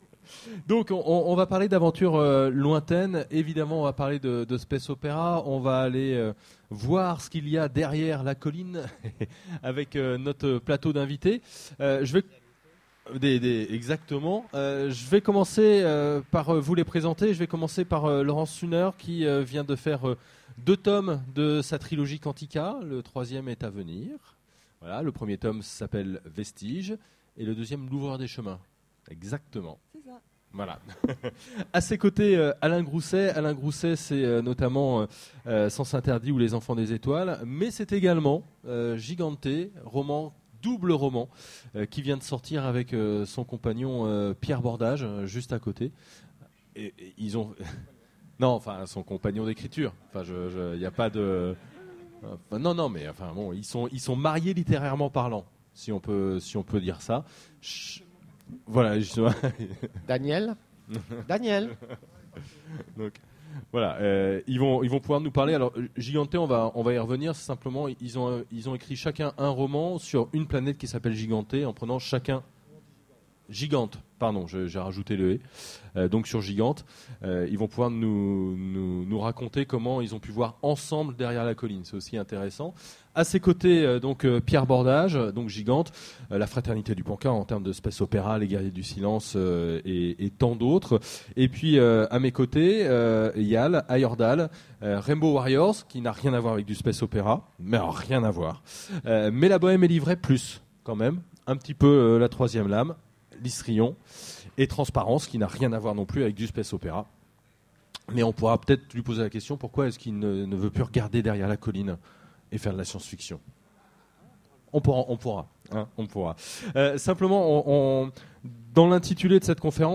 Utopiales 13 : Conférence Aventures lointaines